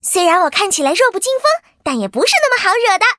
文件 文件历史 文件用途 全域文件用途 Fifi_tk_03.ogg （Ogg Vorbis声音文件，长度3.2秒，101 kbps，文件大小：39 KB） 源地址:游戏语音 文件历史 点击某个日期/时间查看对应时刻的文件。